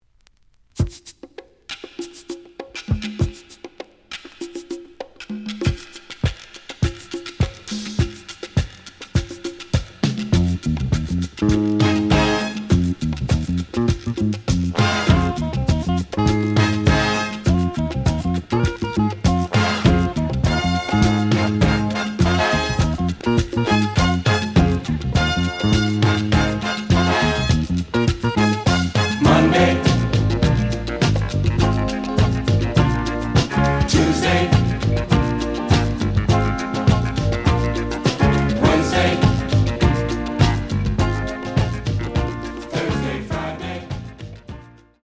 試聴は実際のレコードから録音しています。
●Genre: Disco
●Record Grading: EX- (盤に若干の歪み。多少の傷はあるが、おおむね良好。)